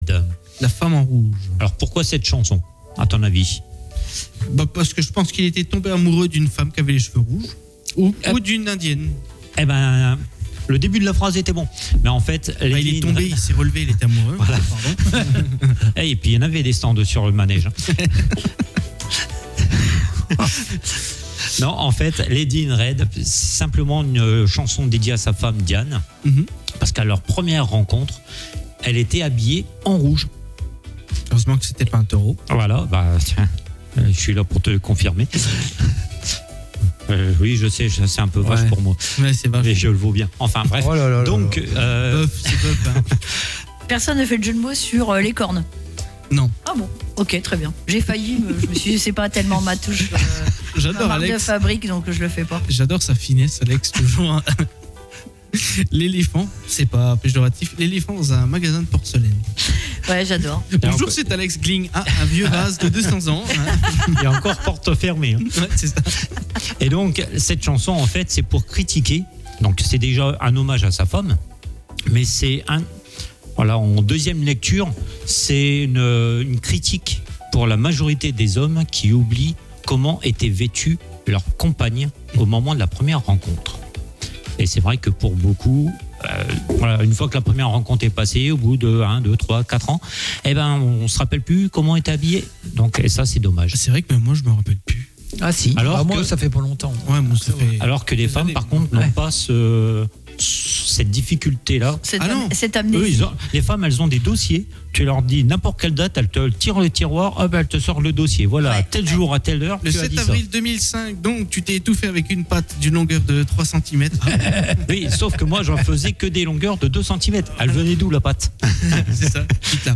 Tous les dimanches de 14h30 à 17h00En direct sur ANTENNE 87À (re)découvrir en podcast sur notre site web